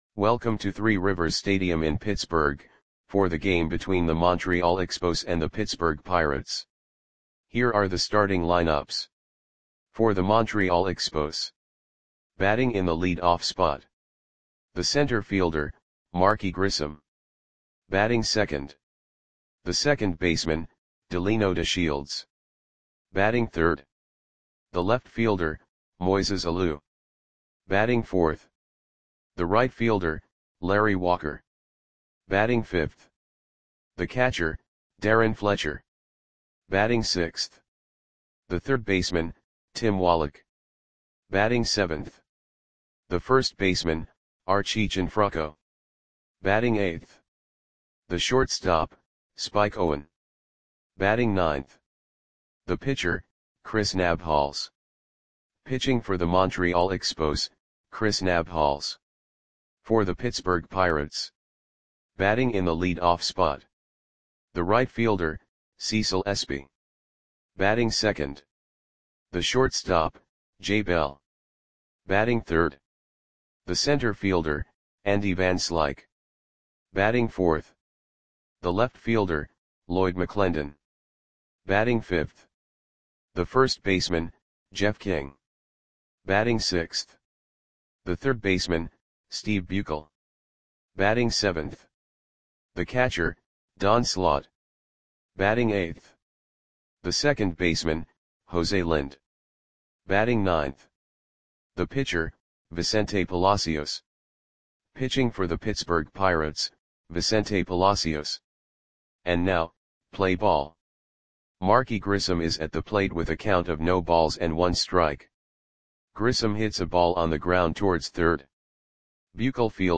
Lineups for the Pittsburgh Pirates versus Montreal Expos baseball game on June 18, 1992 at Three Rivers Stadium (Pittsburgh, PA).
Click the button below to listen to the audio play-by-play.